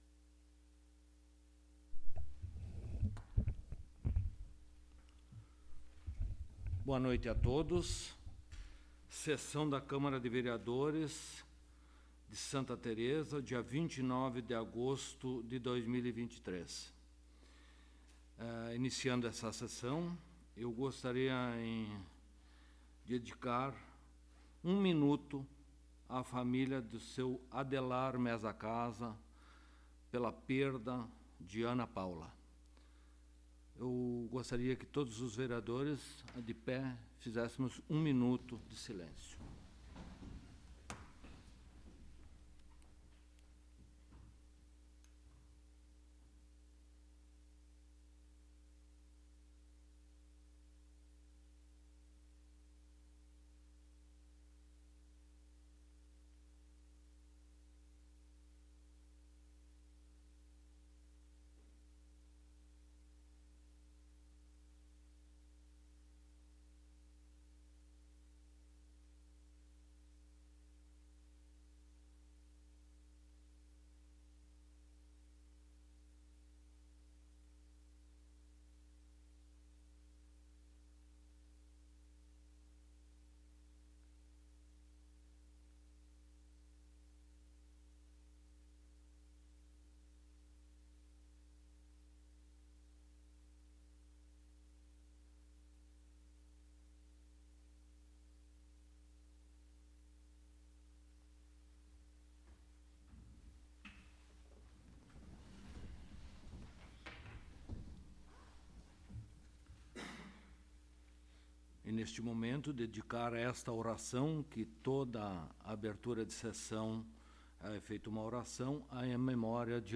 14ª Sessão Ordinária de 2023